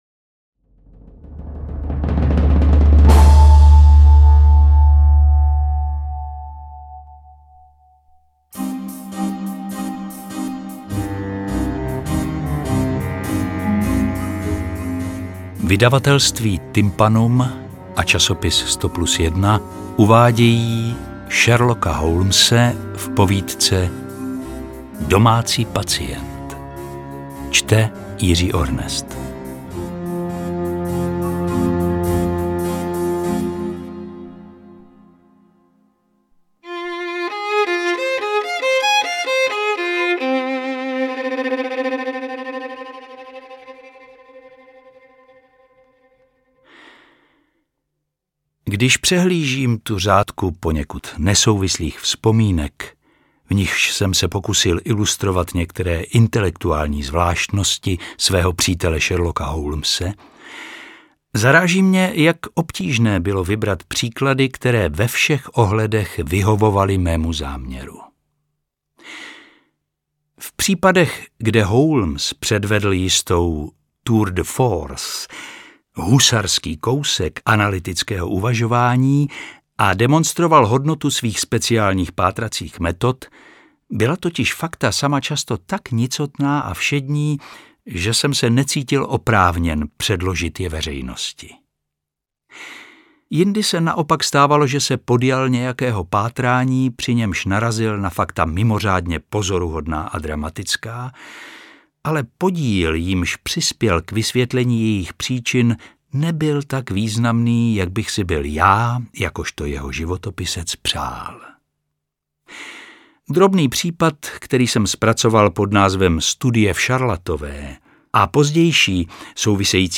Interpret:  Jiří Ornest